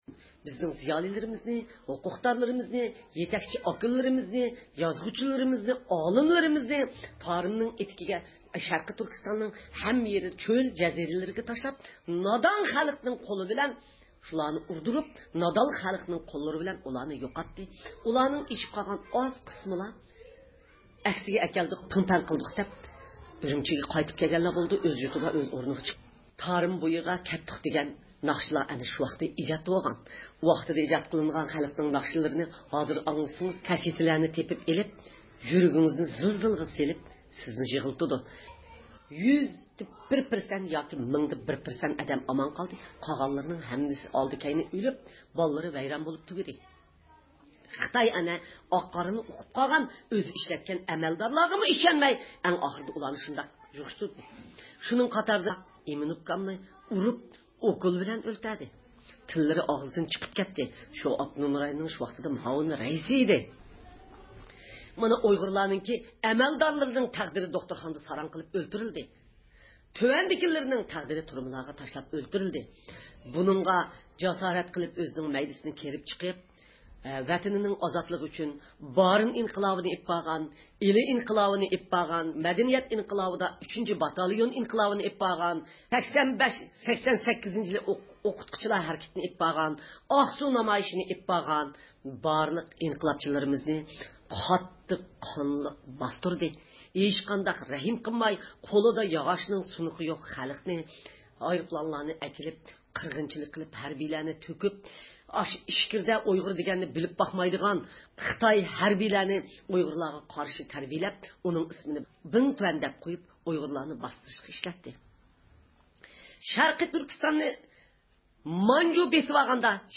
ئۇيغۇر مىللىي ھەركىتىنىڭ رەھبىرى، دۇنيا ئۇيغۇر قۇرۇلتىيىنىڭ رەئىسى رابىيە قادىر خانىم گېرمانىيىنىڭ ميۇنخېن شەھىرىدىكى زىيارىتى داۋامىدا شەرقى تۈركىستان ئىنفورماتسيۇن مەركىزى تەسىس قىلغان ئۇيغۇر تېلېۋىزىيىسىدە نوتۇق سۆزلىدى.